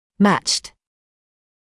[mæʧt][мэчт]сопоставимый, сравнимый, подобный